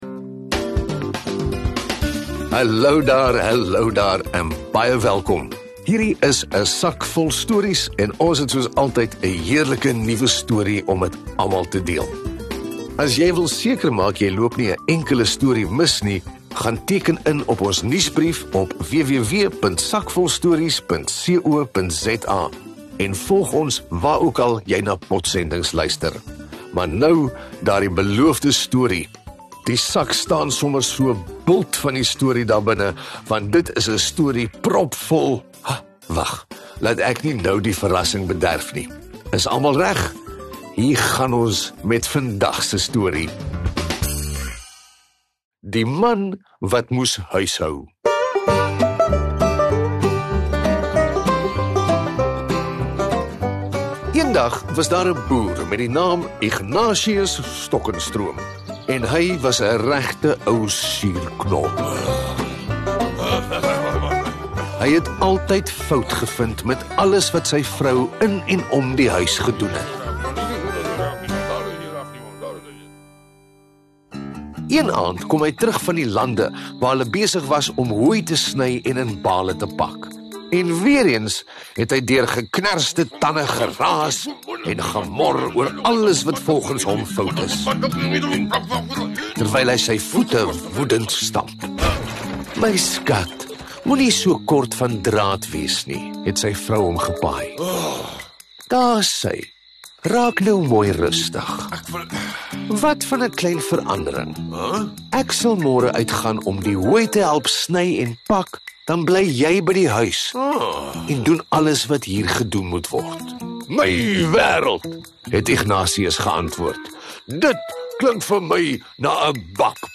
'n Sak Vol Stories S01E07 Die Man Wat Moes Huishou Play episode August 21 10 mins Bookmarks Episode Description 'n Afrikaanse storiepodsending propvol avontuur en verbeelding vir kinders van alle ouderdomme.